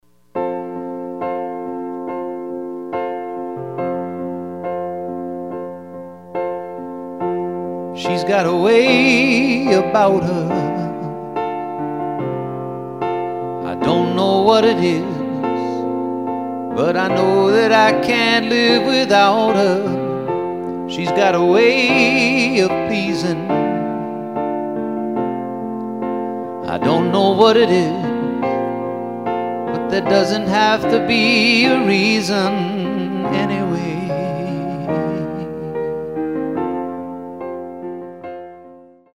Love songs in an intimate piano/vocal presentation